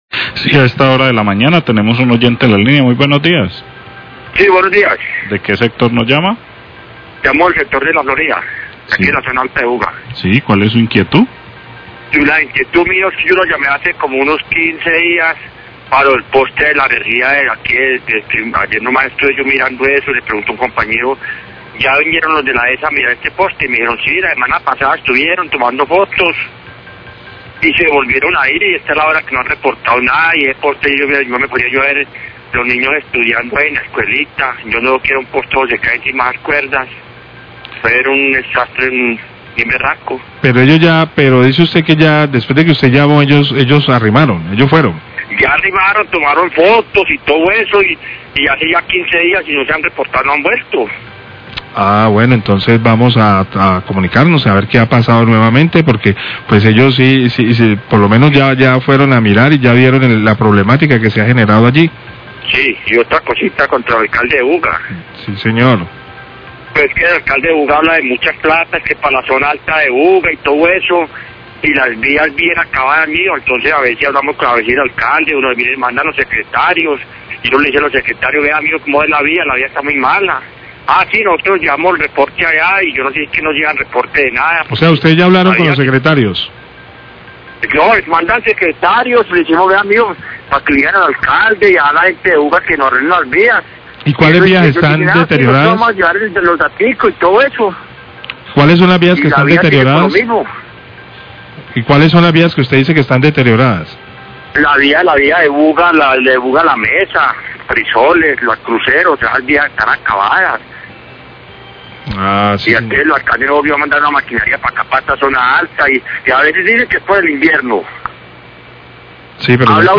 Radio
queja oyente